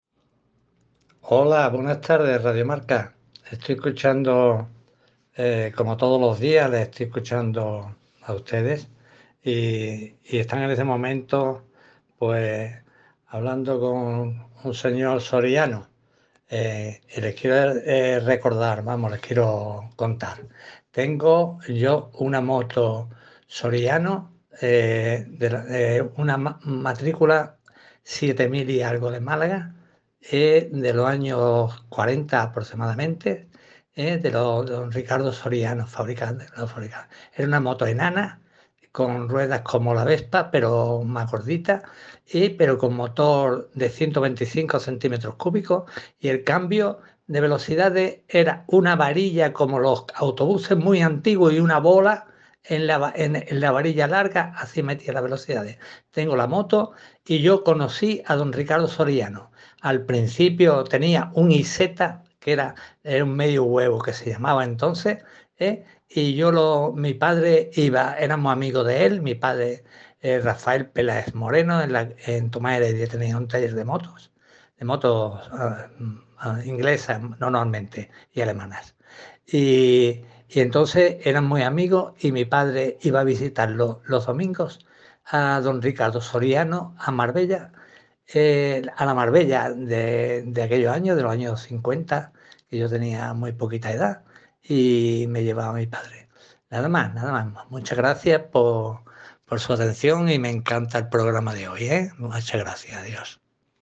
Audio de oyente y cliente de Grupo Soriano
OYENTE-online-audio-converter.com_.mp3